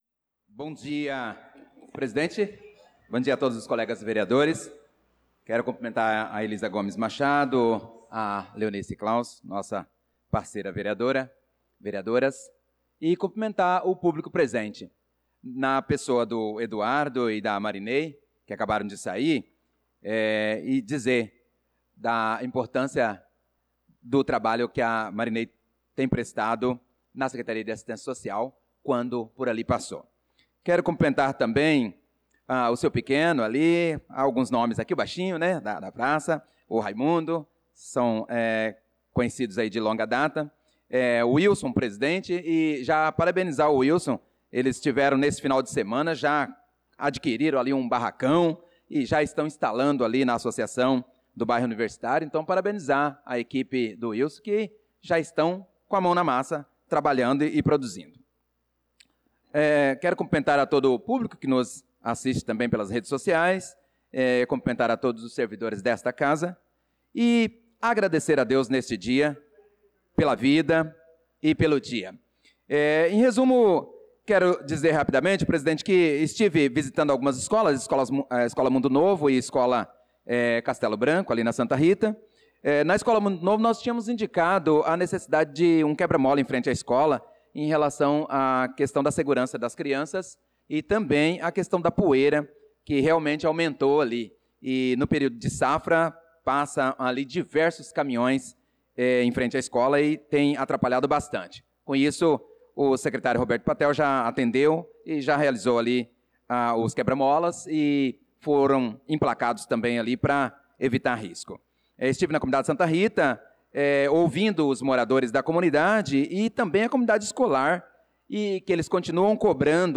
Pronunciamento do vereador Prof. Nilson na Sessão Ordinária do dia 02/06/2025